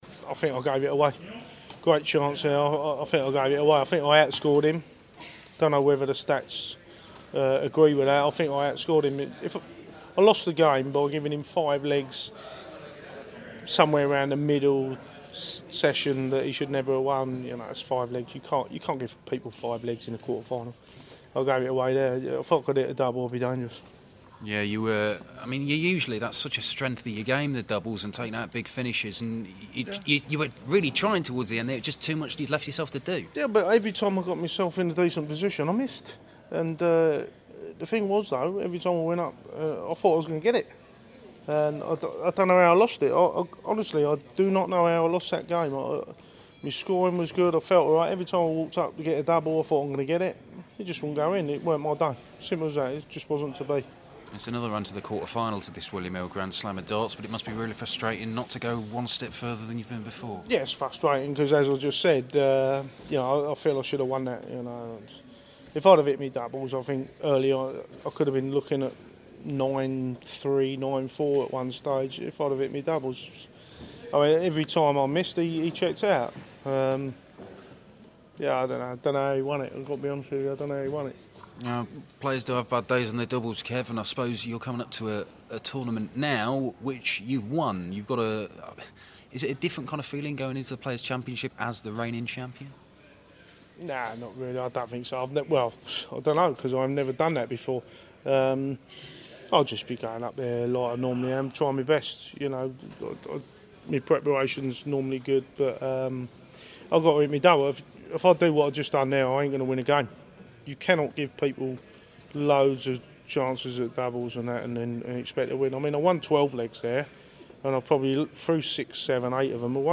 William Hill GSOD - Painter Interview (QF)